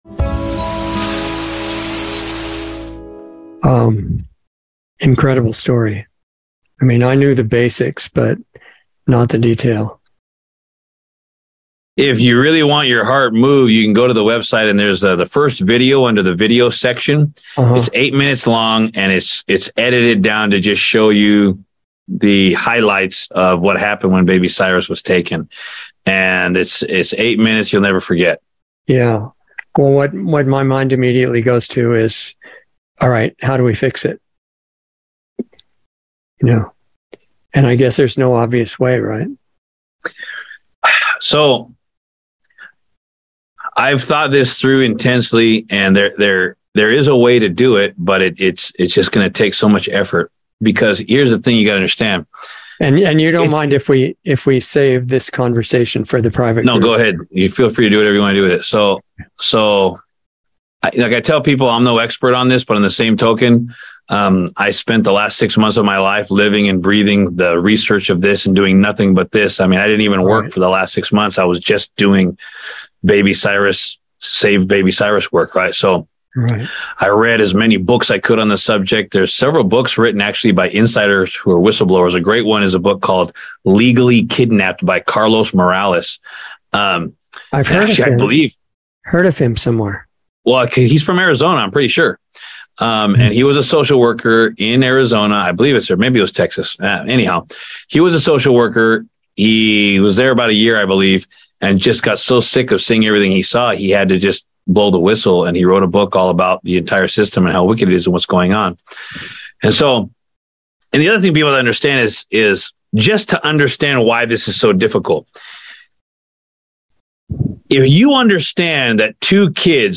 Insider Interview 8/3/22